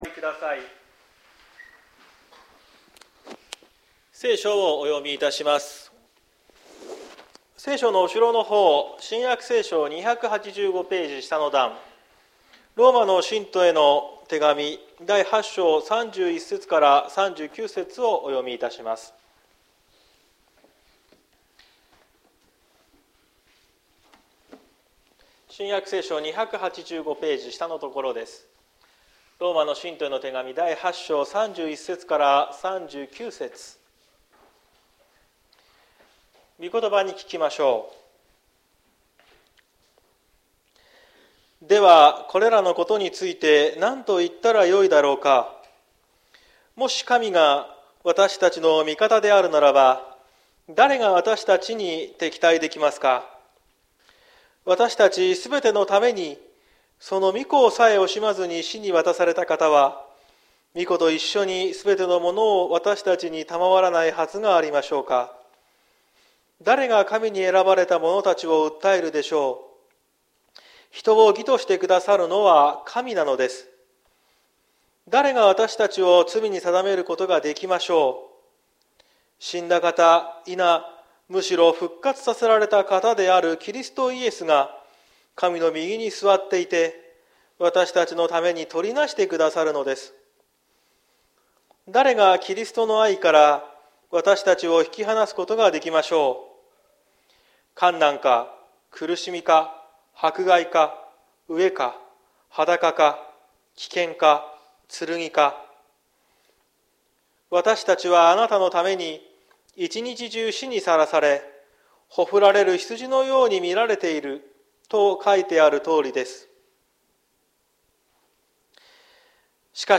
2023年10月29日朝の礼拝「輝かしい勝利」綱島教会
説教アーカイブ。